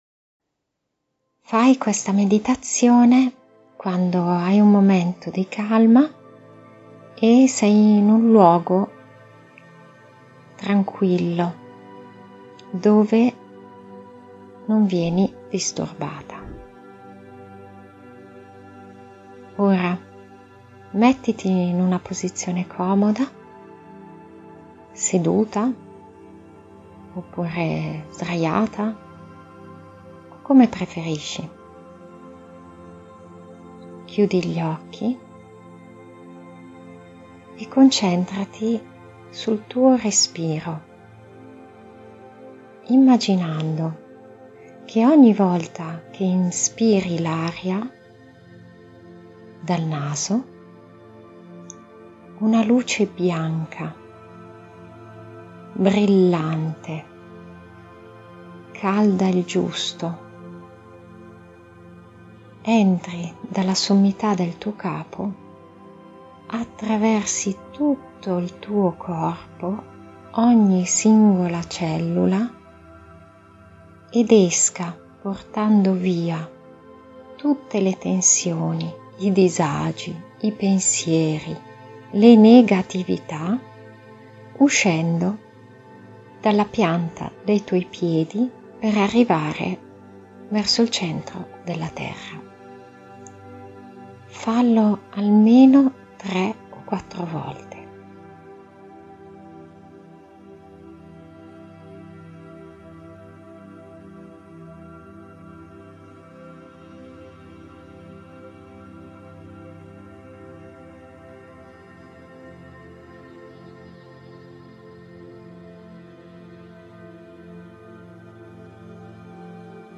Meditazione del cuore